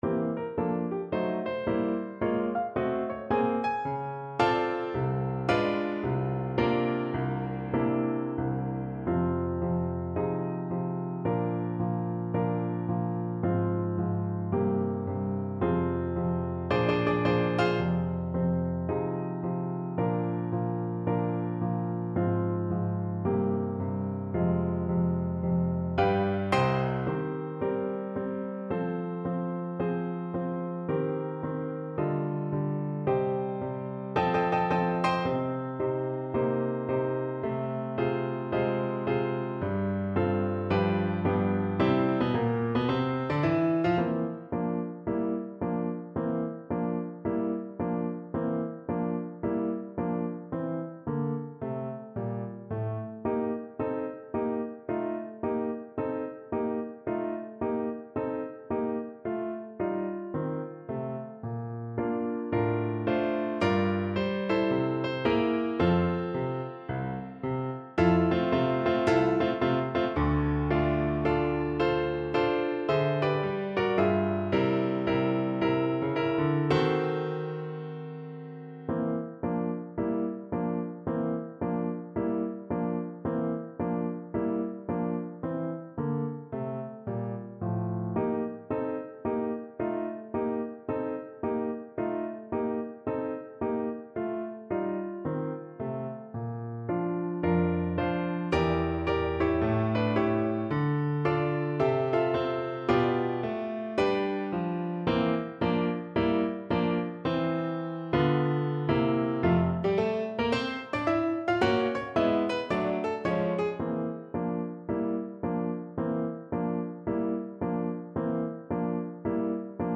French Horn version
Moderato =110 swung
4/4 (View more 4/4 Music)
Classical (View more Classical French Horn Music)